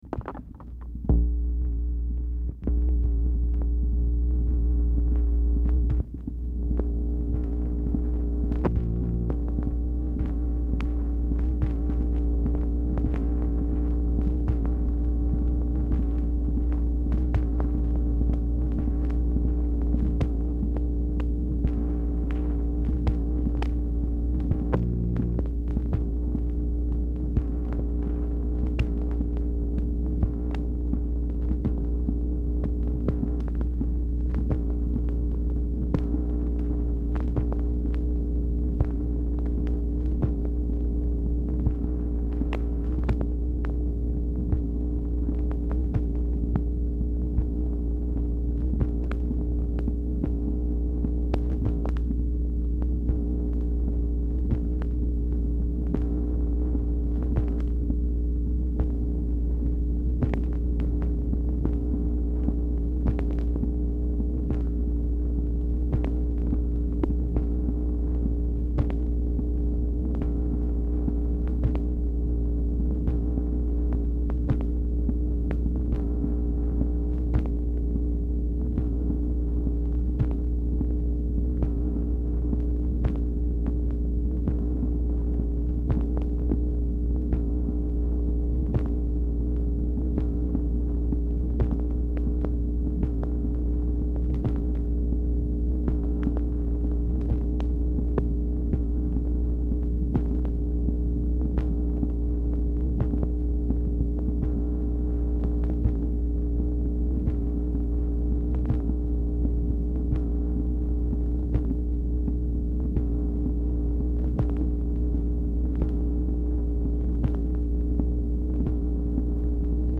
Telephone conversation # 1940, sound recording, MACHINE NOISE, 2/7/1964, time unknown | Discover LBJ
Format Dictation belt
Specific Item Type Telephone conversation